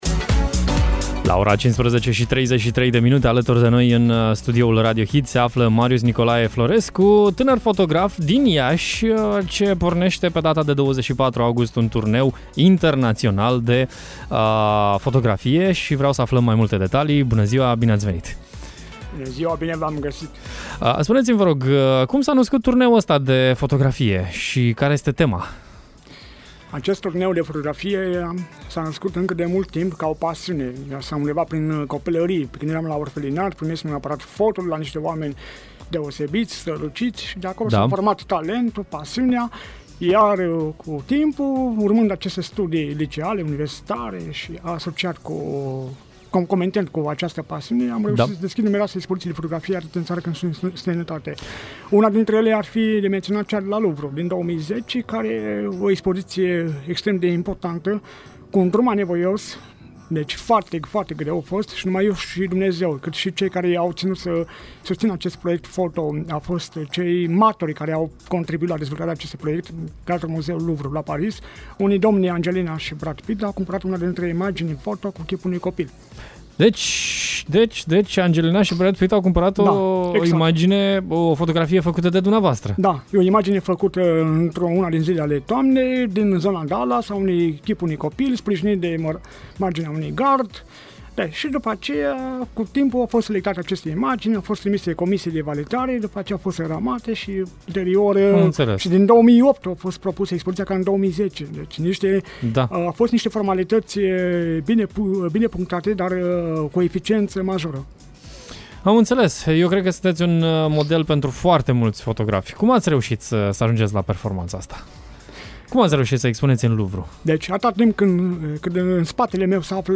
De această dată expoziția de fotografie se numește „Fețe din umbră.” Această expoziție va debuta pe 24 august la Piatra Neamț, iar apoi va continua la Timișoara, Cluj, București, Berlin și Torino. Mai multe detalii am aflat în direct la Radio Hit